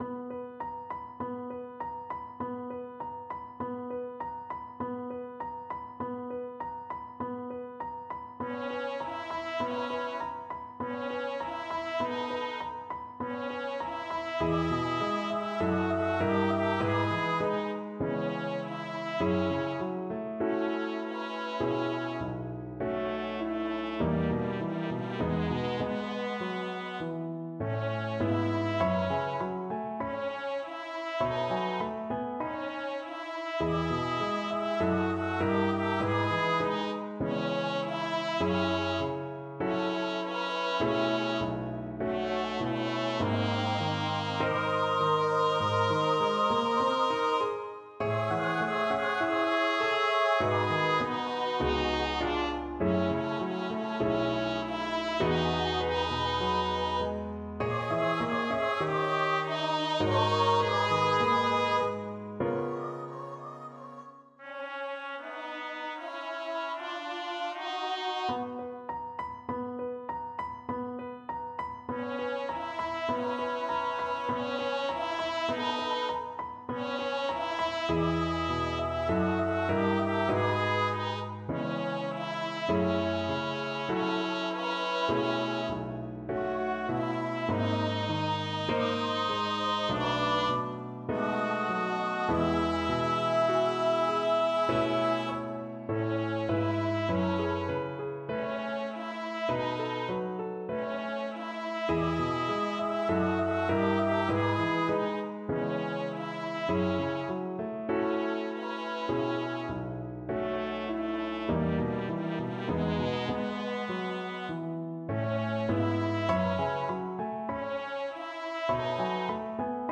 00 Wonka Pure Imagination Vs For Mp3 Rehearsal Alto Unknown
00_wonka-Pure-Imagination-vs-for-mp3-rehearsal-alto_unknown.mp3